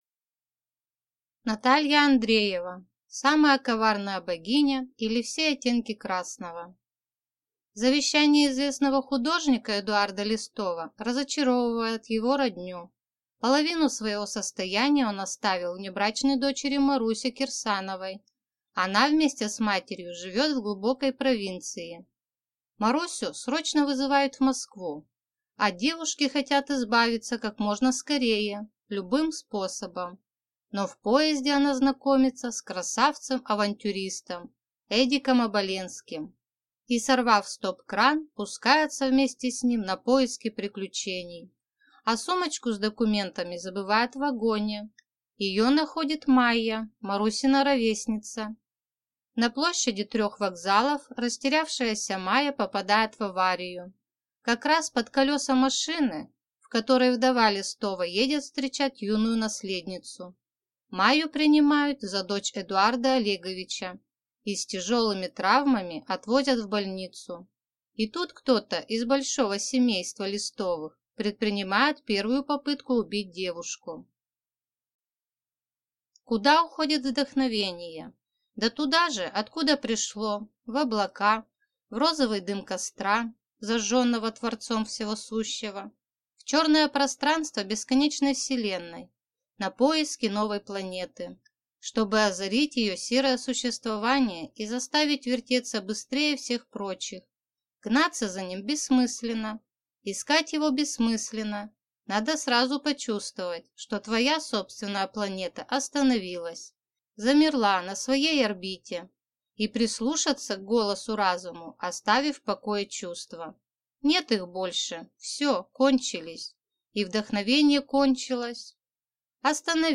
Aудиокнига Самая коварная богиня, или Все оттенки красного